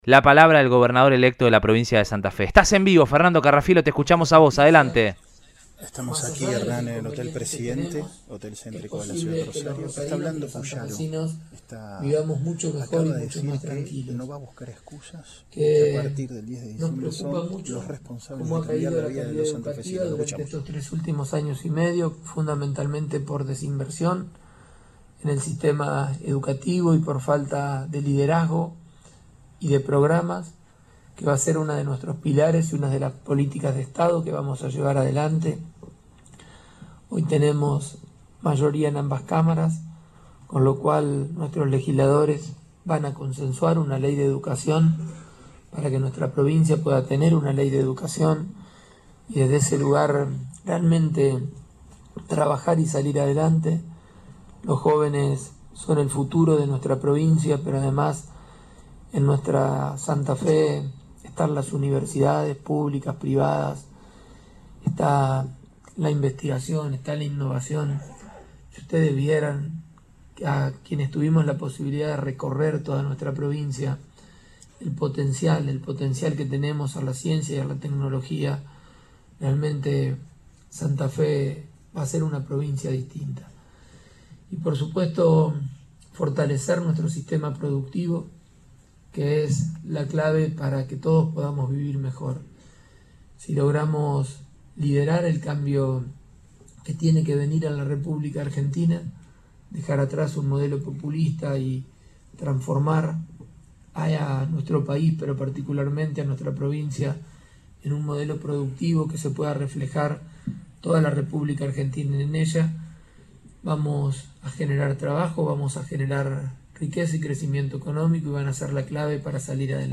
El gobernador electo brindó una conferencia de prensa tras el triunfo y dijo que "Santa Fe va a ser una provincia distinta".